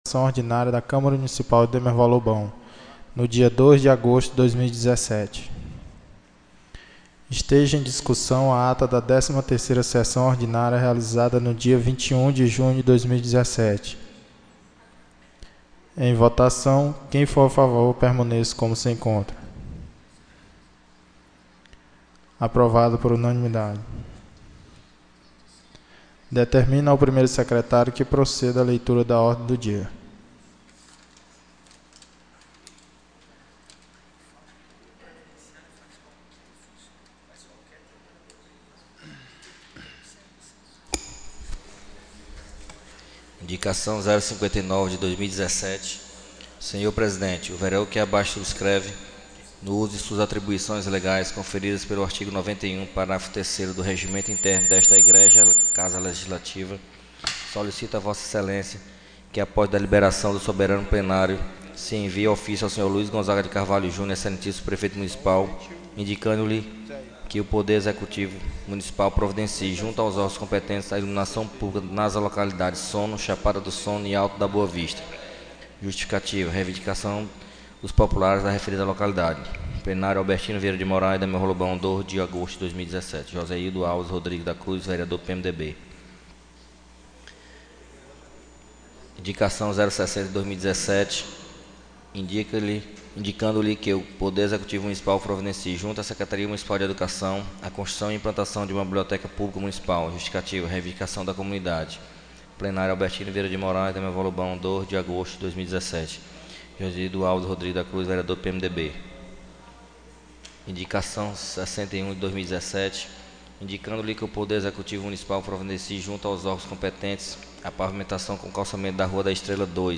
14ª SESSÃO ORDINÁRIA 02/08/2017